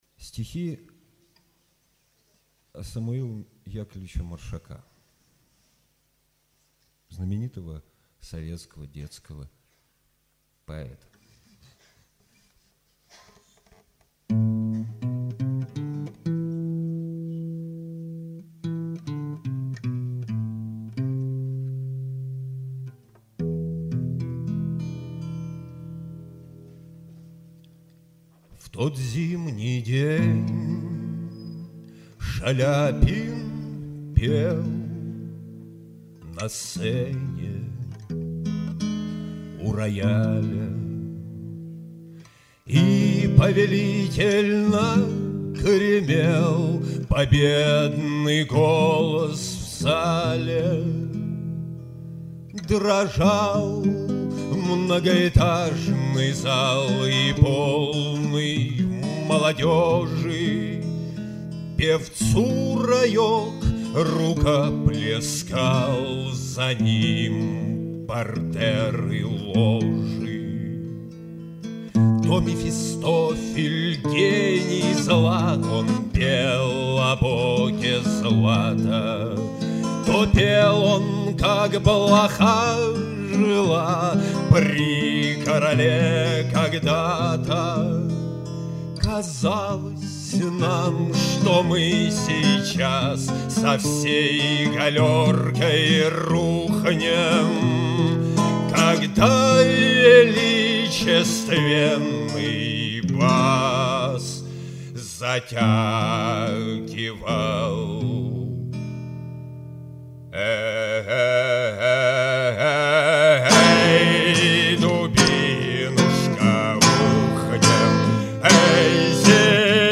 Авторская песня
Играет на 6 и 12-ти струнной гитарах.